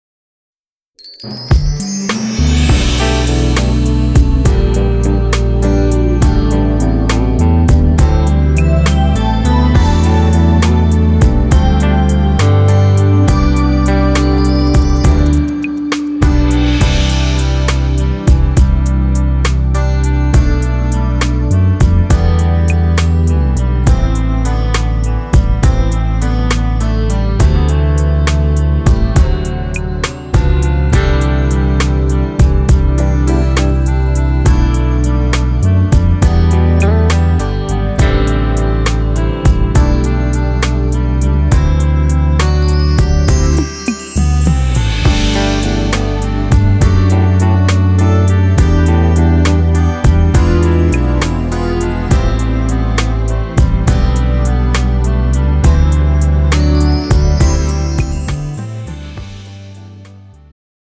음정 남자-2키
장르 축가 구분 Pro MR